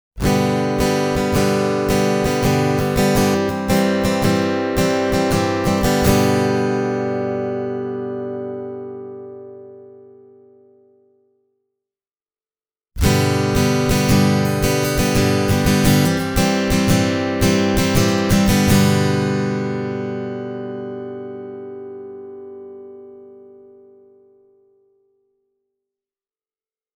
This Farida gives you the punch you’d associate with a well-made dreadnought guitar, suitably seasoned with the warmth this model’s Gibson-type scale length brings into the mix.
A piezo-only pickup system is always a bit of a compromise, in terms of sound fidelity, but the Isys T does a good job. In these clips the first phrase has been recorded with Contour off and the second phrase with Contour on: